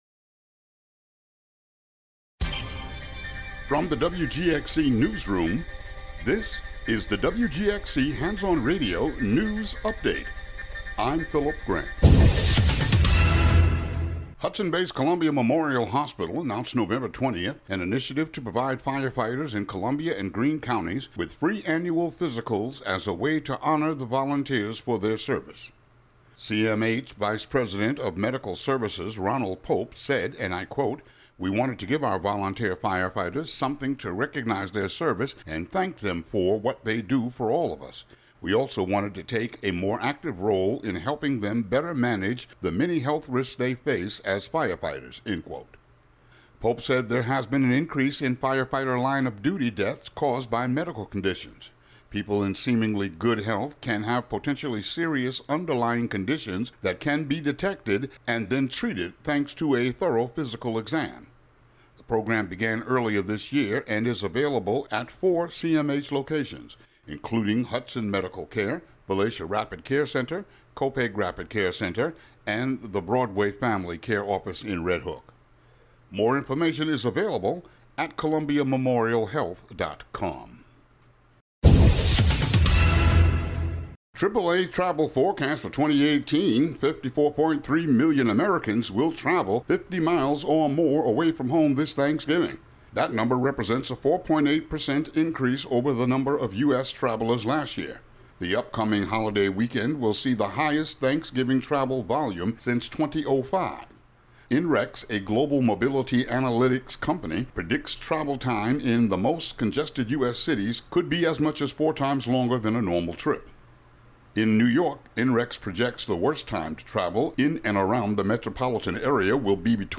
News update for the area.